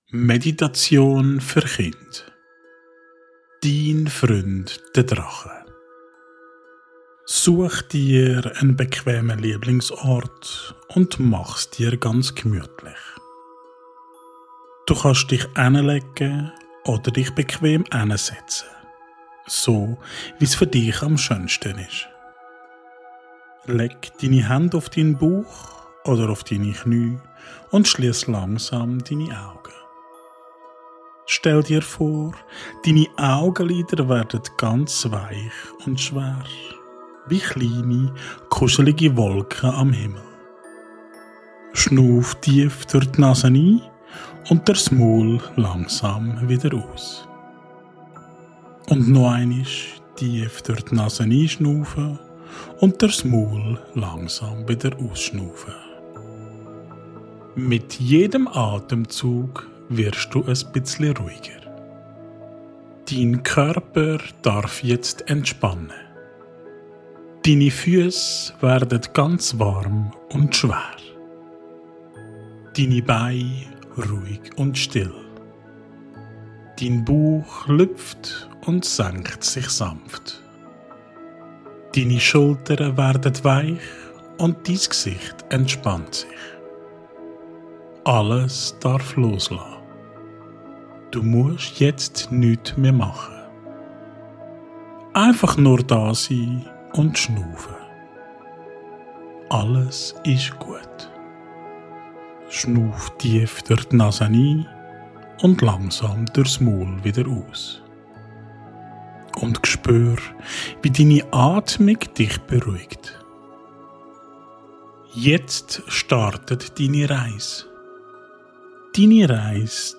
Diese geführte Meditation ist für Kinder gemacht, die ihre innere Stärke entdecken und sich sicher, mutig und geborgen fühlen möchten.
Durch sanfte Bilder und beruhigende Worte lernen Kinder, sich zu entspannen, ihren Körper besser wahrzunehmen und ihre Gefühle zu verstehen.